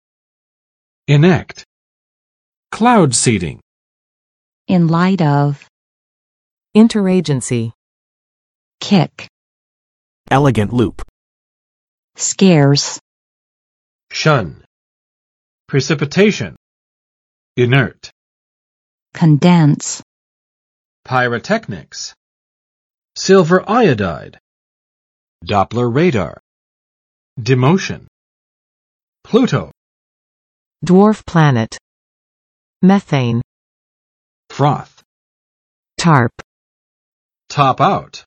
[ɪnˋækt] v. 制定（法律）；颁布（法案）